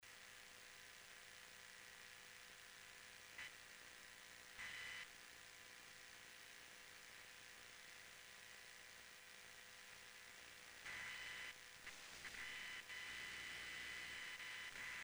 Getting a lot of PC noise (graphic noise and stuff) as well!
Here you can hear me switch windows focus, and dragging the explorer window then switching back to Cubase!
But the background static still come and go!
Everything else routed internally via Scope v5.
it really sounds like leaking stuff to analog...
the higher pitched noise comes when you move your mouse.
noise.mp3